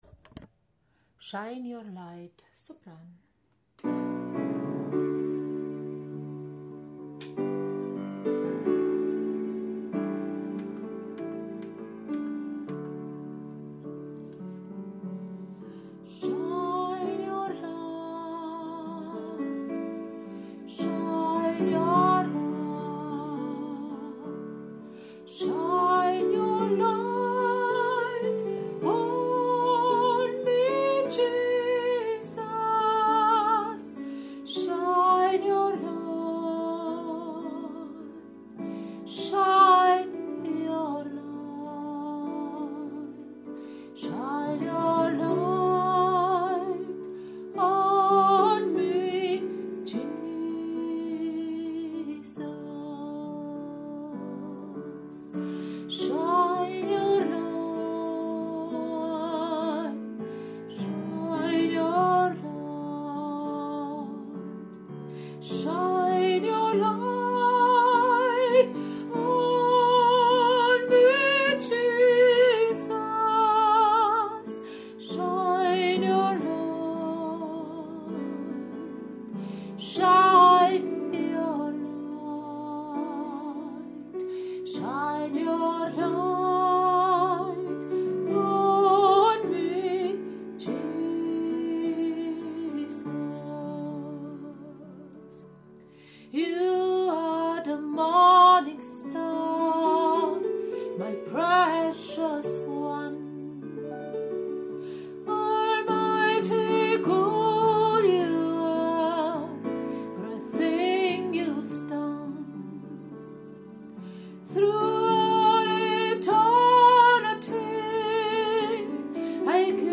Shine your light Sopran
Shine-your-light-Sopran.mp3